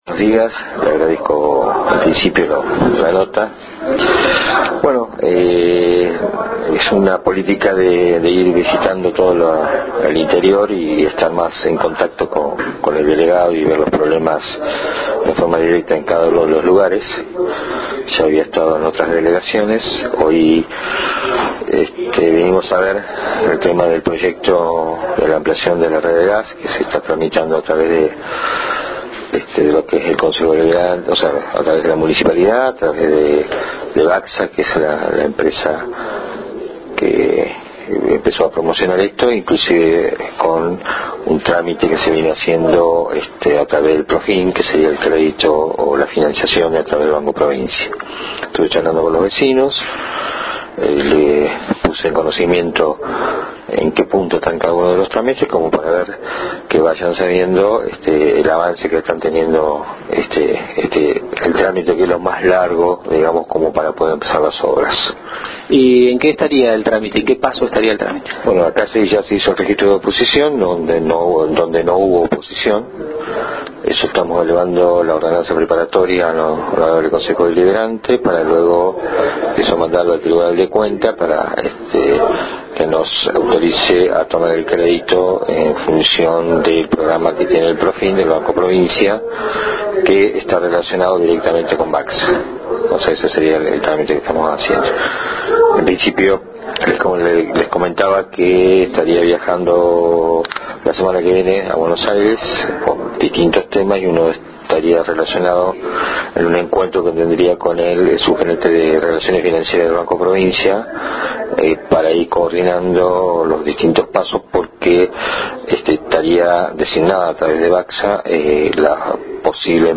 EL SECRETARIO DE OBRAS PÚBLICAS  EN J.N.F.